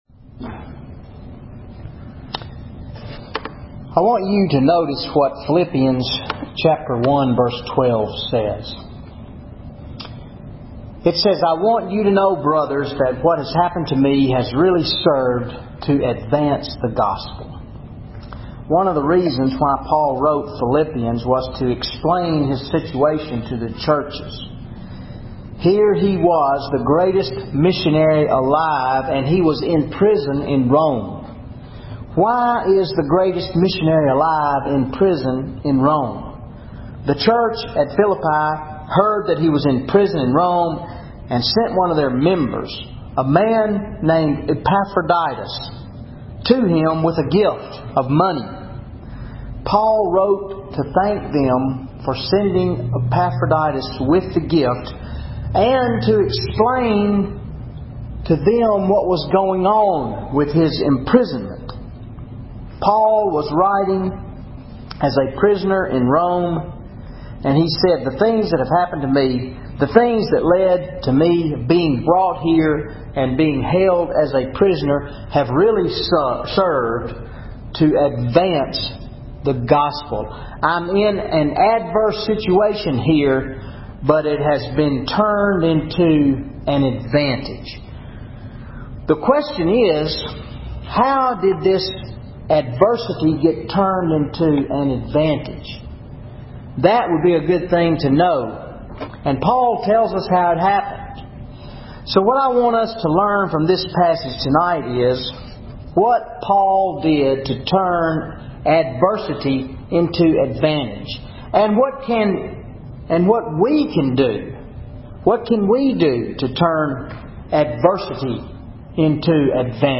Wednesday Night Bible Study Philippians 1:12-18 Advancing Through Adversity
Sermon Audio